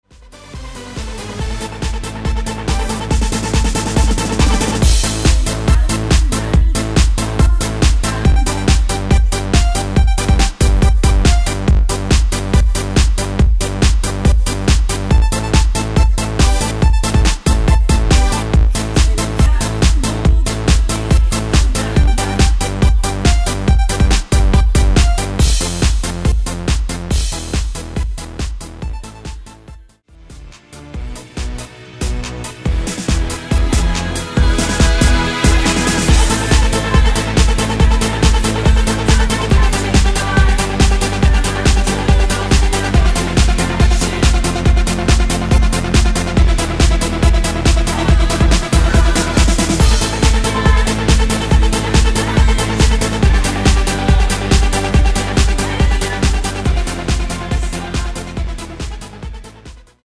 원키(-1)내린 코러스 포함된 MR 입니다.(미리듣기 참조)
Am
앞부분30초, 뒷부분30초씩 편집해서 올려 드리고 있습니다.